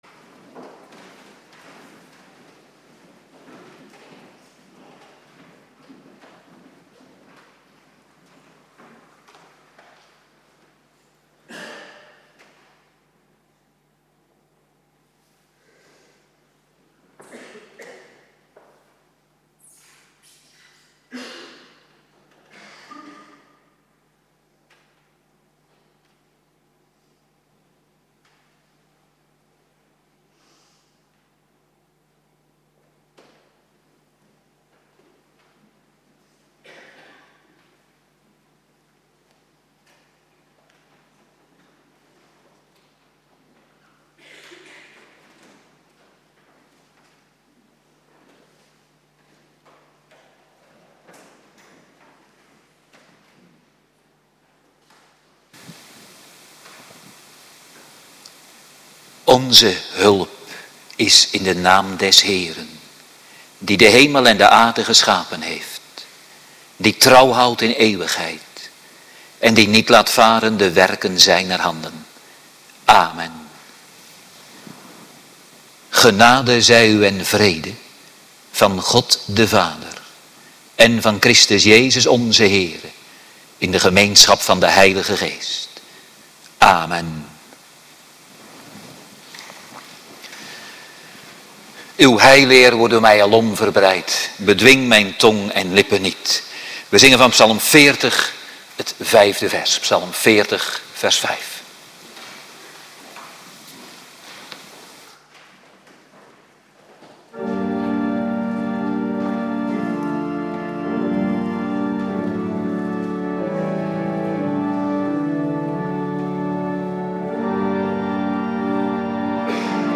Woensdagavonddienst